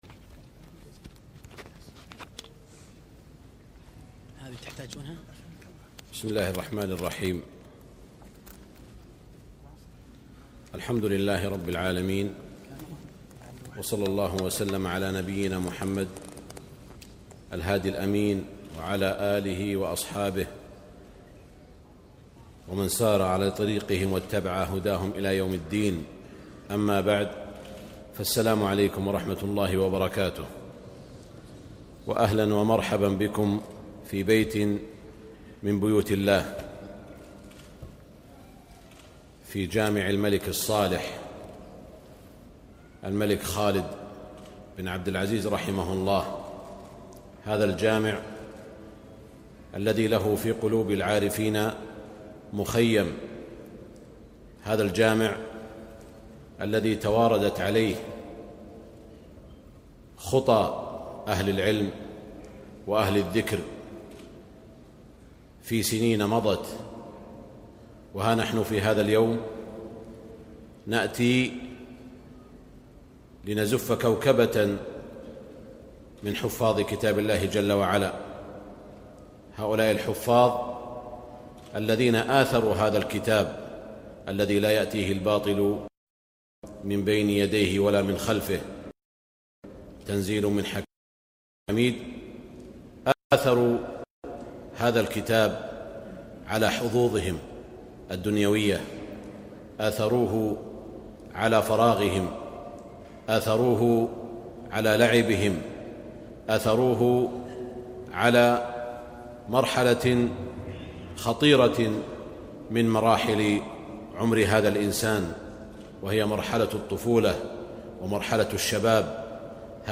كلمة فضيلة الشيخ أ.د. سعود الشريم في حفل حلقات مجمع جامع الملك خالد رحمه الله > زيارة الشيخ سعود الشريم للرياض عام 1436هـ > تلاوات وجهود أئمة الحرم المكي خارج الحرم > المزيد - تلاوات الحرمين